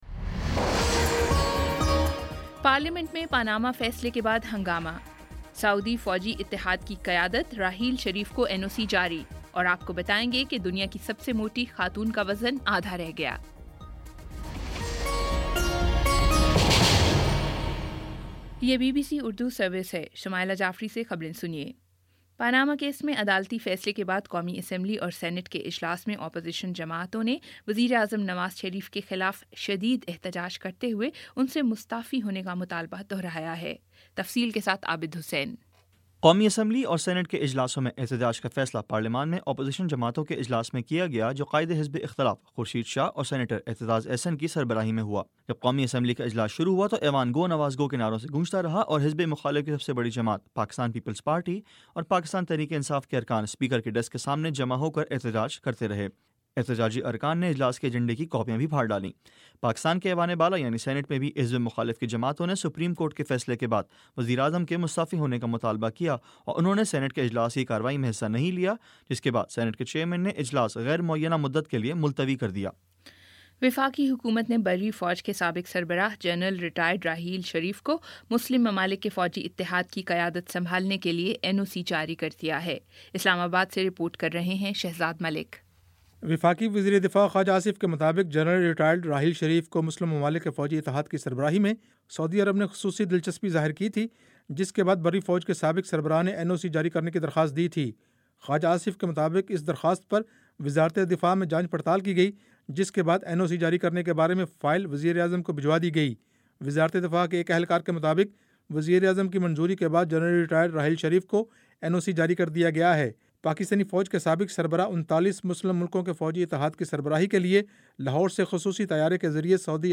اپریل 21 : شام سات بجے کا نیوز بُلیٹن